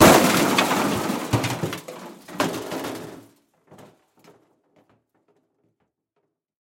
Звук столкновения автомобиля на скорости с пешеходом